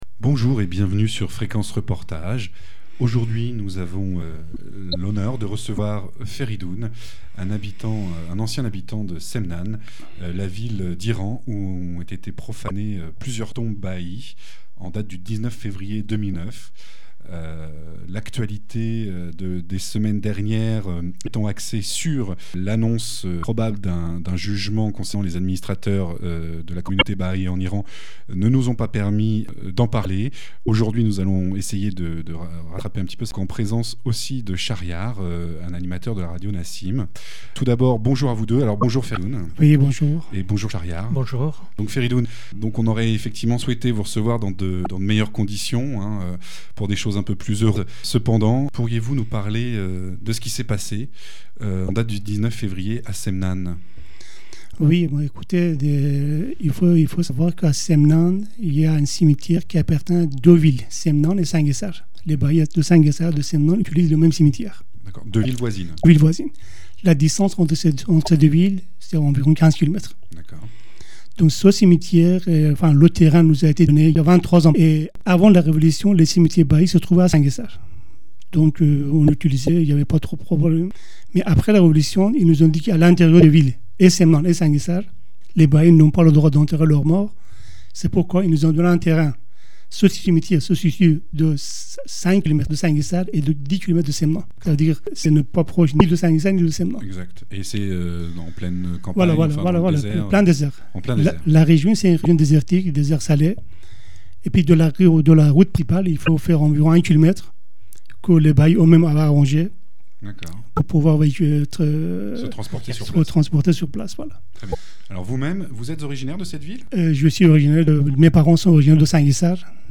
Fréquence reportage - Témoignage sur l'Iran